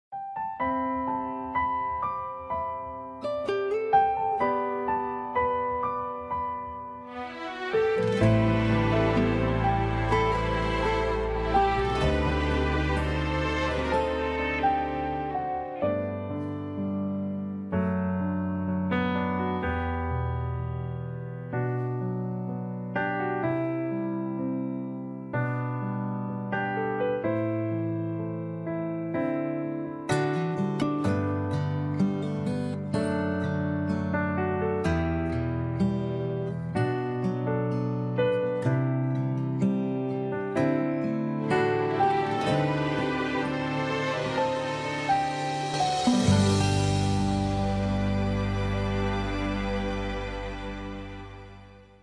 钢琴曲